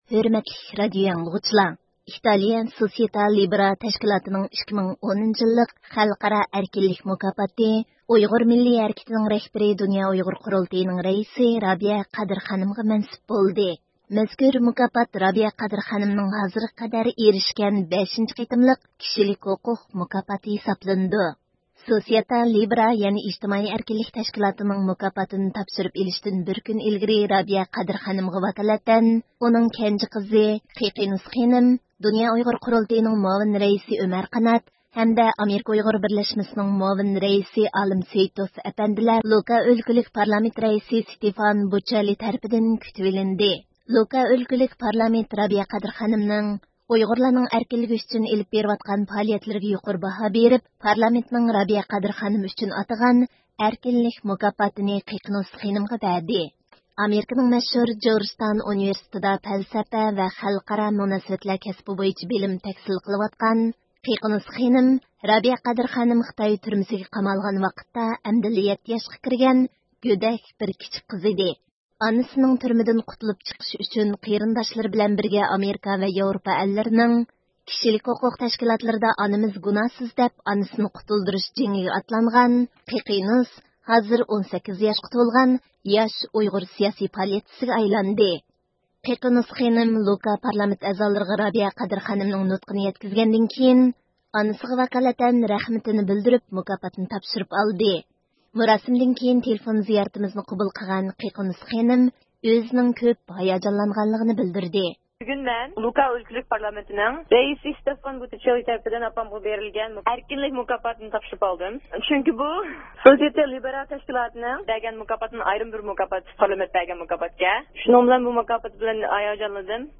ﻣﯘﺧﺒﯩﺮﯨﻤﯩﺰ
مۇراسىمدىن كېيىن تېلېفۇن زىيارىتىمىزنى قوبۇل قىلغان